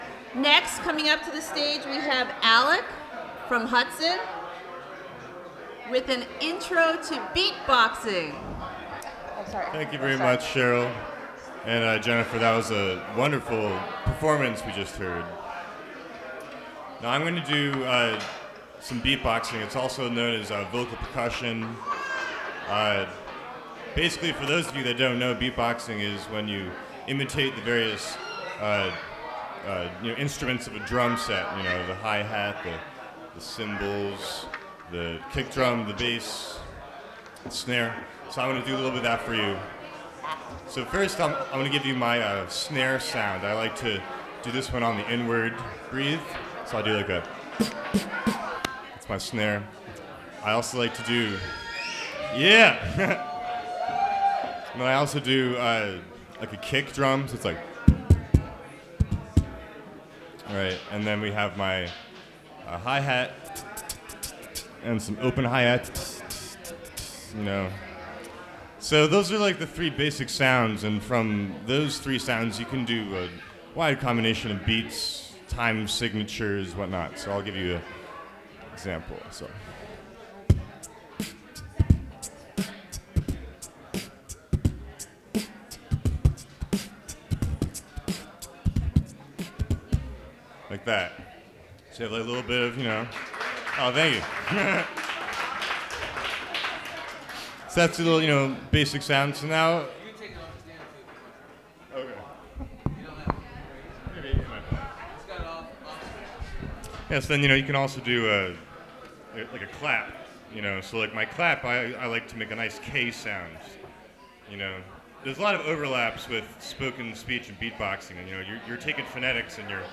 Recorded from WGXC 90.7-FM webstream.